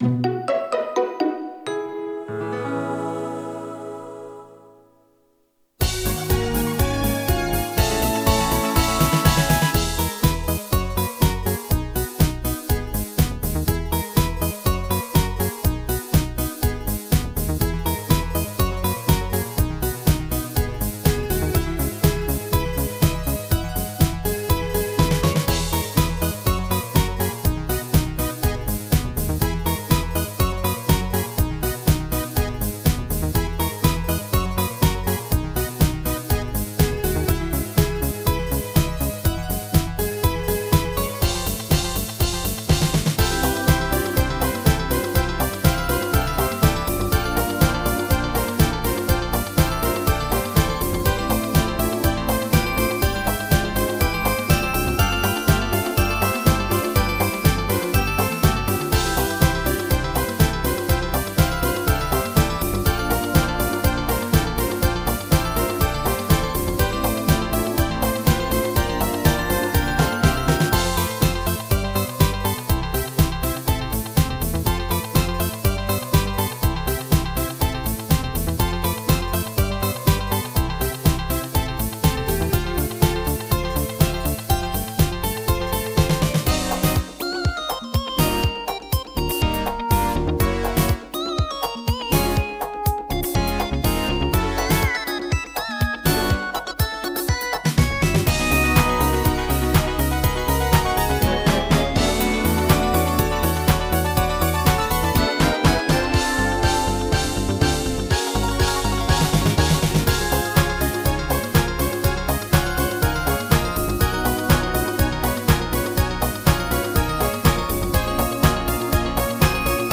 BPM122-125
Audio QualityPerfect (High Quality)